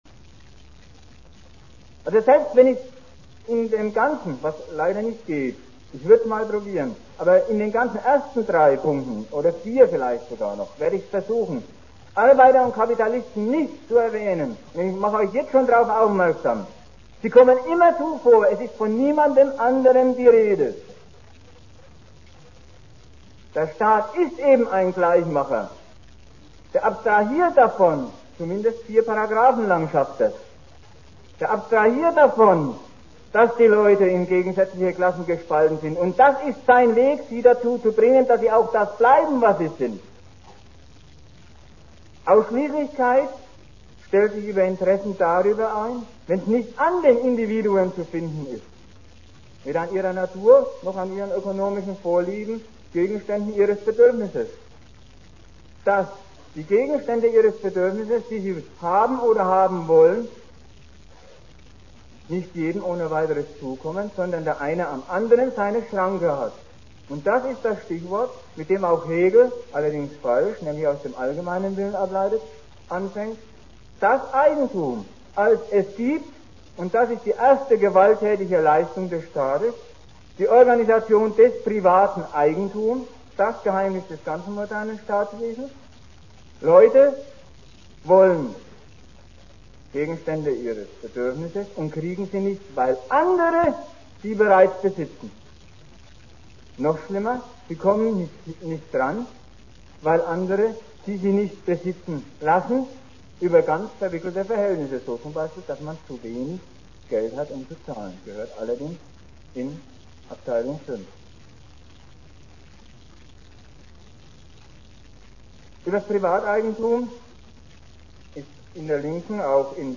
Vortrag zum gleichnamigen Buch: Der bürgerliche Staat (Resultate Nr.3), das beim GegenStandpunkt Verlag zu bekommen ist.